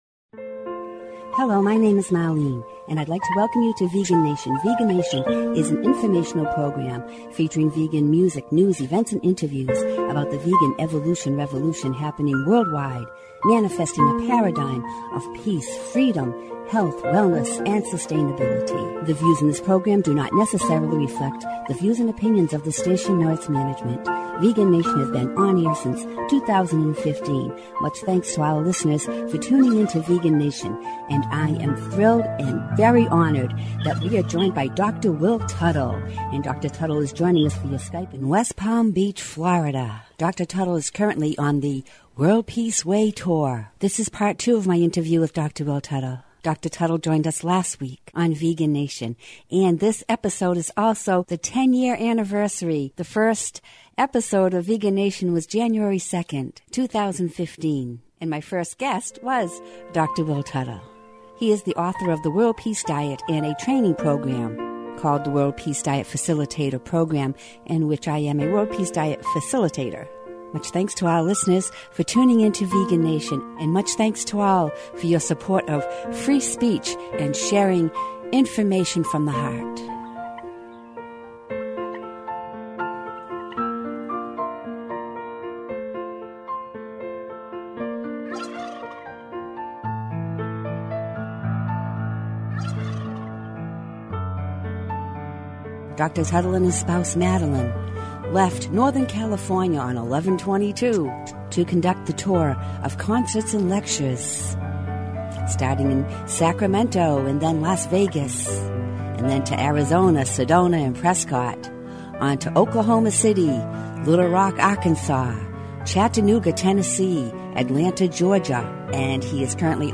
Vegan Nation, a half-hour radio show, On-Air since 2015.